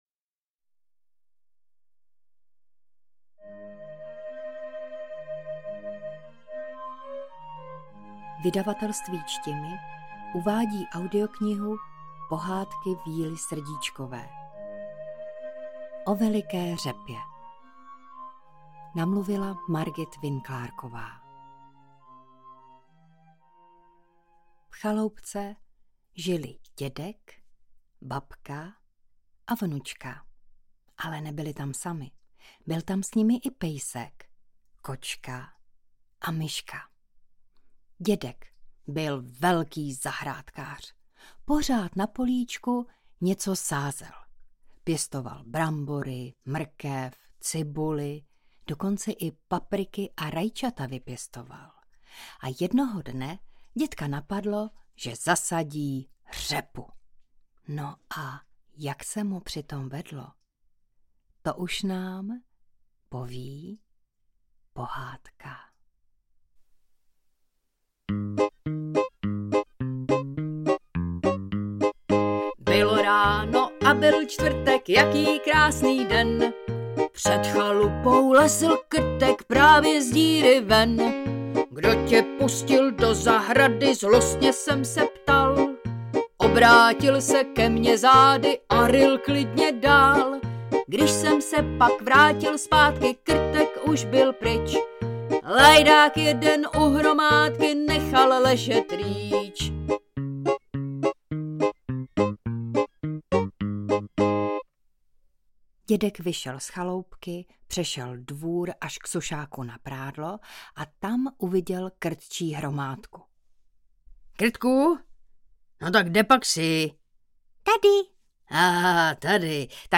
Pohádka O veliké řepě je plná veselých písniček, trochu se v ní ráčkuje, a končí poučením, že je dobře, když si vzájemně pomáháme.